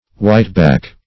whiteback - definition of whiteback - synonyms, pronunciation, spelling from Free Dictionary Search Result for " whiteback" : The Collaborative International Dictionary of English v.0.48: Whiteback \White"back`\, n. (Zool.) The canvasback.